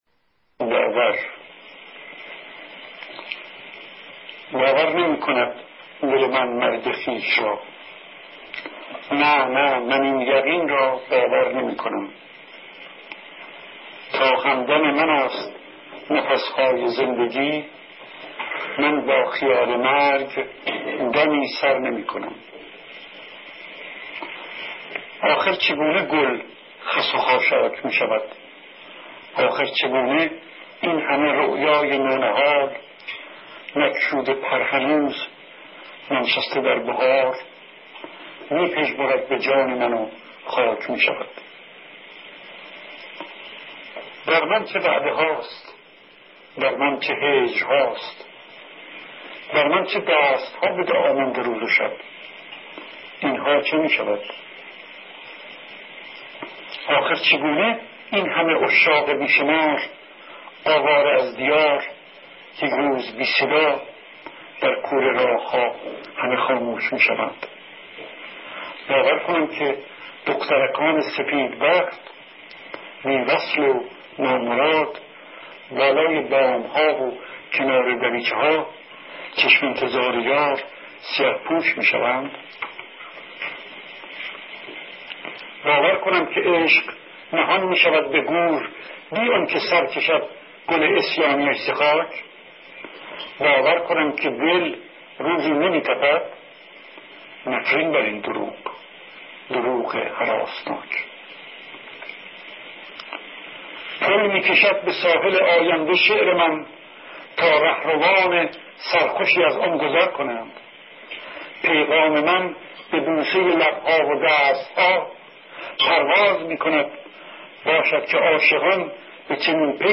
شعر باور سرودة سیاوش کسرایی را با صدای خود شاعر در